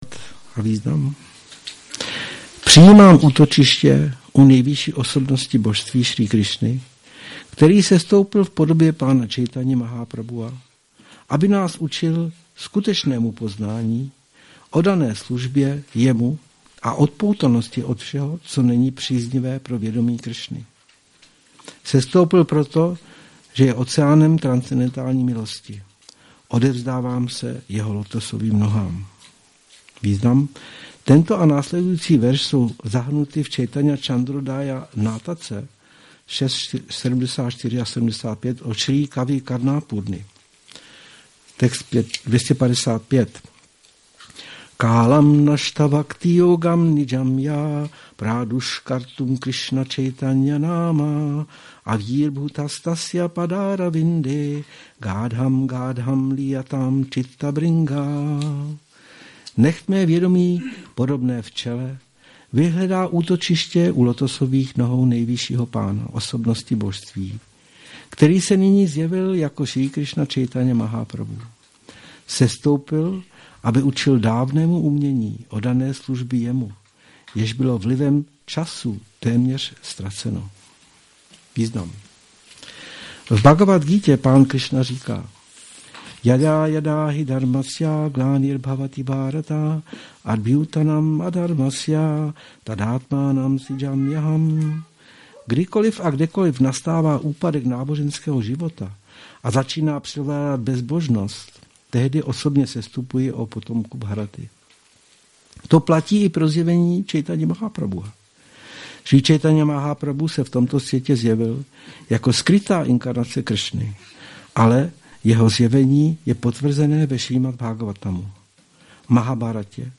Přednáška CC-MAD-3.98
Šrí Šrí Nitái Navadvípačandra mandir